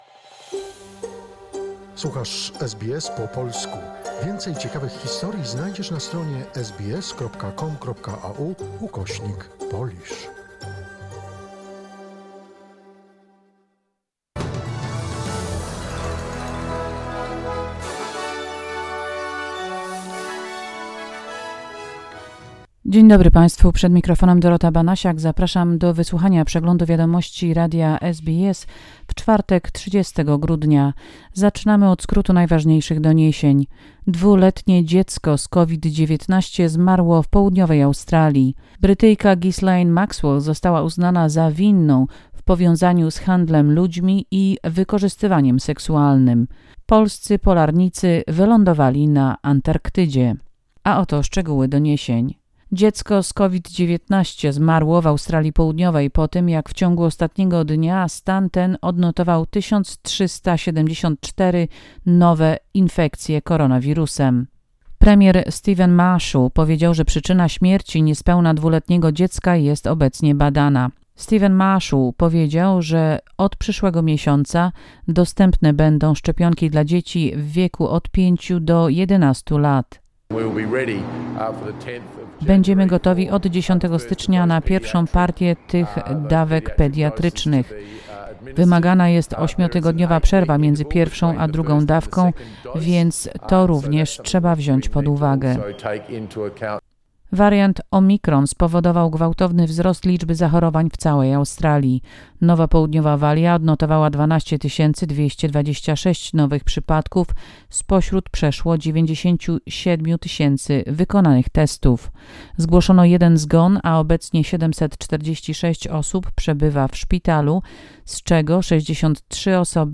SBS News Flash in Polish, 30 December 2021